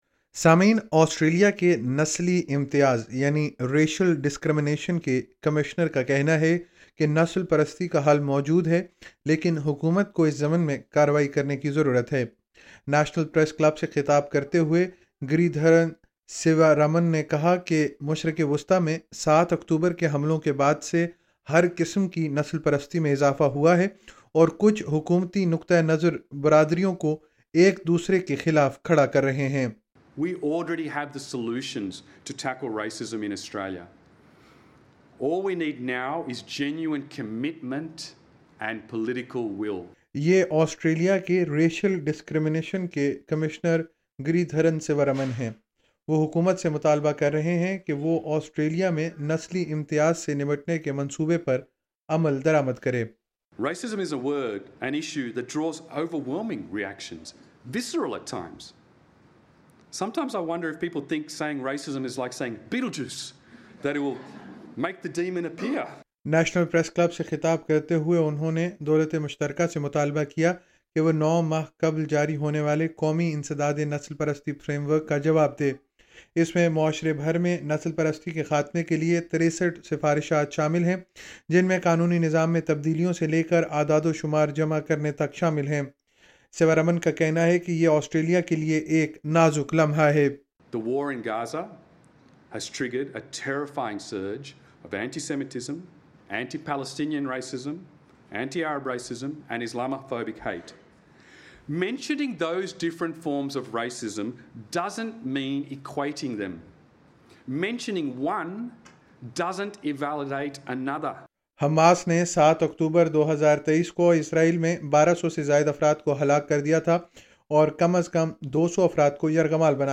LISTEN TO 'اس ملک میں نفرت کے لیے کوئی جگہ نہیں ہے': نسلی امتیاز کمشنر SBS Urdu 07:02 Urdu نیشنل پریس کلب سے خطاب کرتے ہوئے گری دھرن سیورامن نے کہا کہ مشرق وسطیٰ میں سات اکتوبر کے حملوں کے بعد سے ہر قسم کی نسل پرستی میں اضافہ ہوا ہے اور کچھ حکومتی نقطہ نظر برادریوں کو ایک دوسرے کے خلاف کھڑا کر رہے ہیں۔